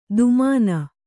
♪ dumāna